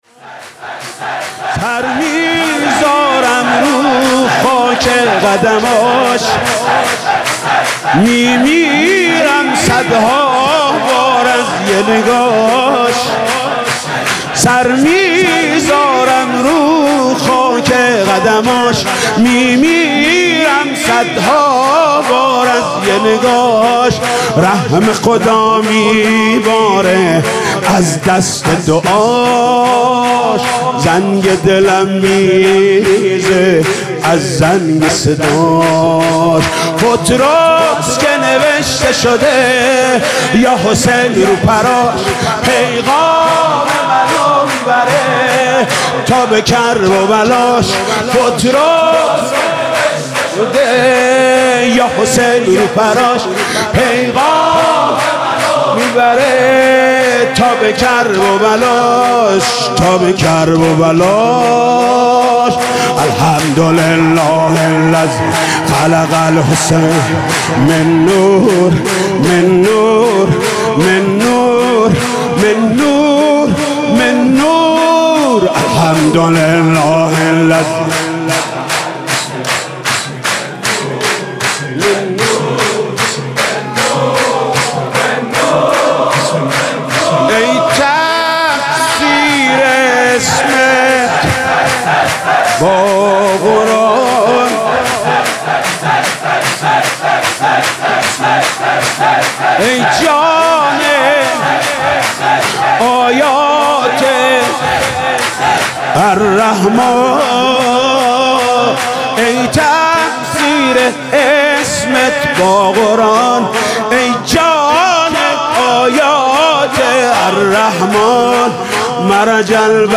سرود: سر میذارم رو خاک قدماش